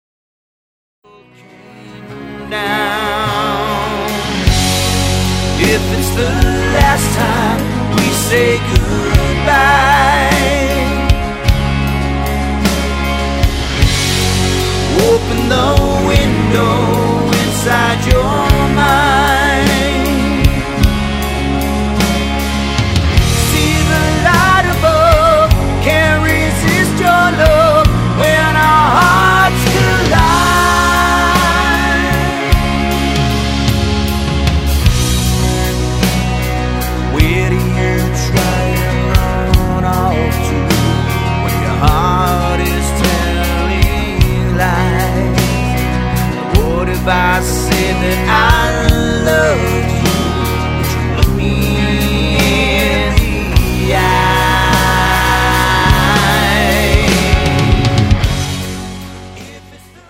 Guitars, keyboards, vocals
Lead Vocals, guitars
Drums, vocals